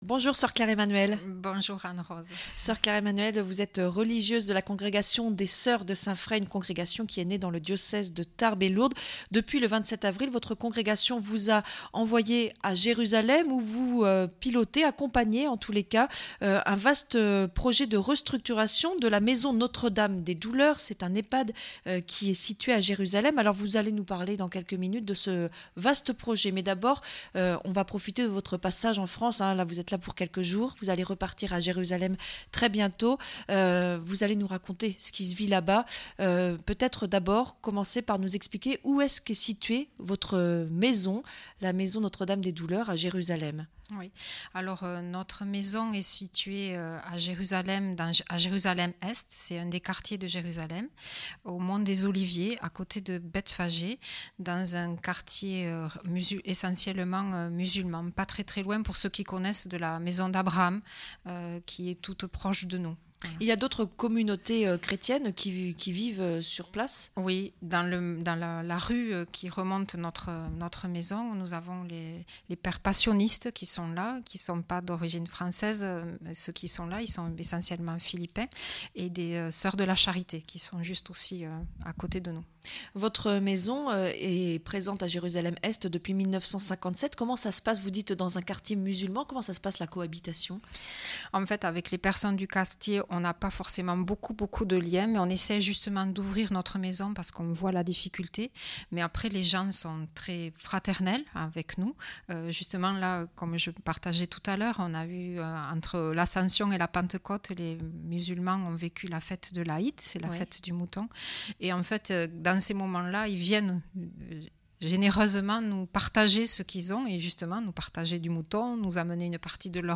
L’Europe est au coeur de l’entretien de Mgr Jea-Marc Micas cette semaine